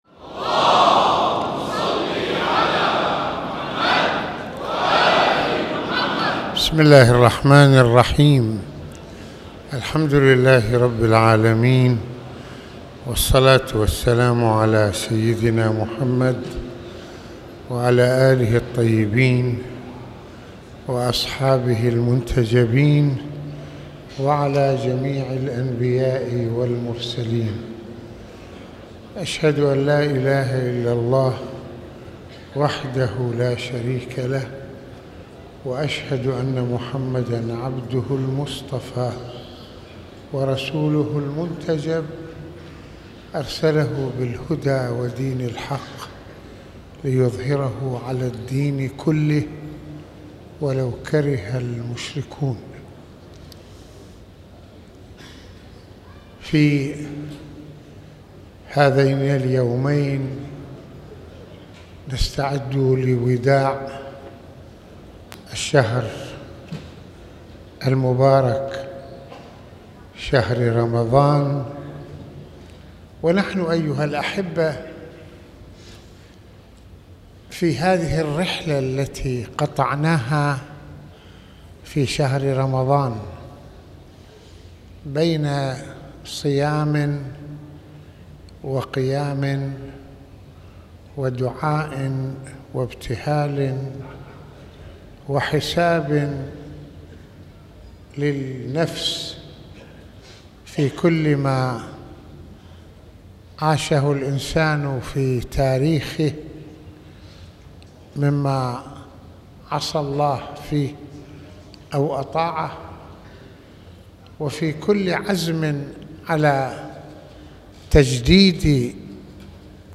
لنجعل من الحياة شهر للطاعة | محاضرات رمضانية